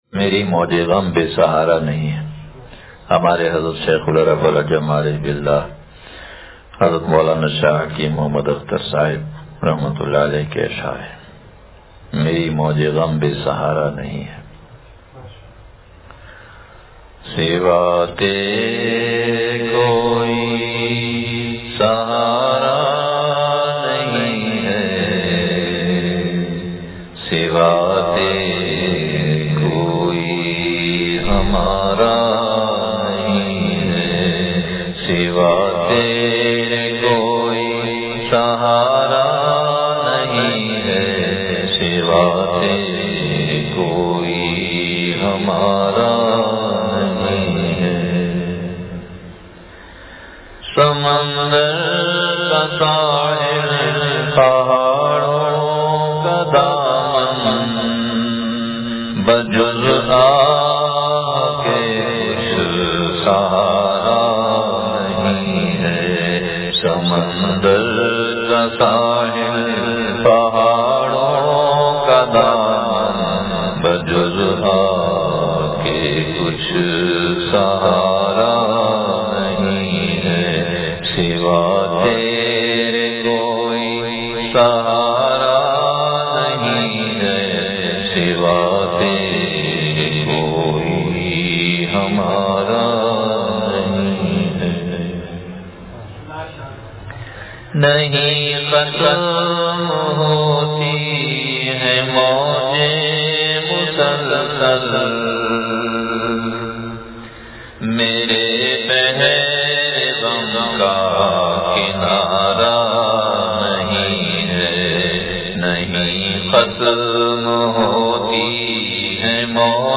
مری موجِ غم بے سہارا نہیں ہے – شیخ العرب والعجم عارف باللہ مجدد زمانہ حضرت والا رحمتہ اللہ علیہ کا وعظ دعا سے پڑھا